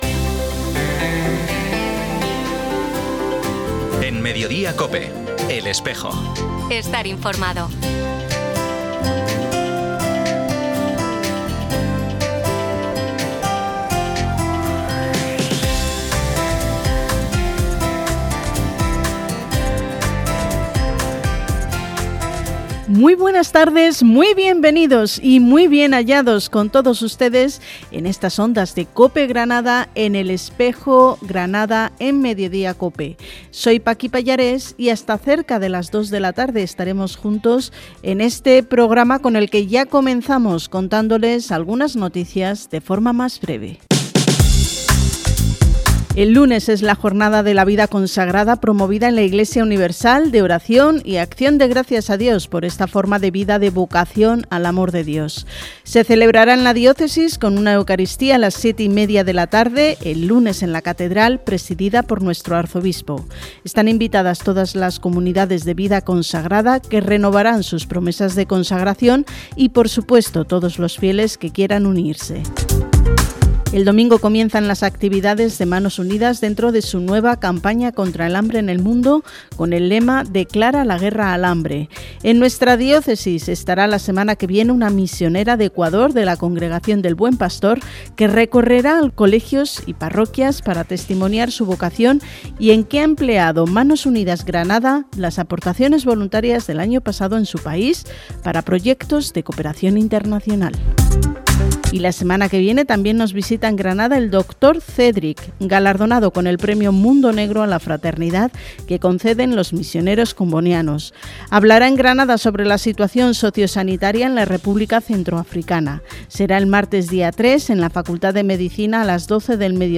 Programa emitido en COPE Granada y COPE Motril, el 30 de enero.